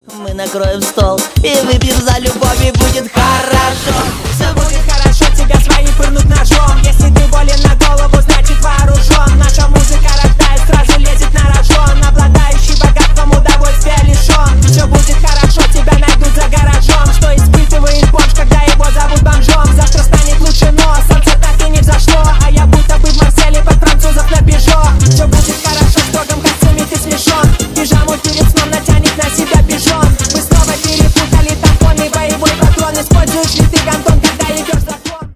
Ремикс # Рэп и Хип Хоп
весёлые # ритмичные # клубные